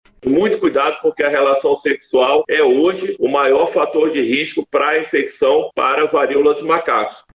Dados apontam que 98% das contaminações ocorrem nas relações sexuais por isso o alerta também é para as mulheres que pretendem engravidar. Como alerta o secretário de saúde a Atenção Primária do Ministério da Saúde, Raphael Câmara.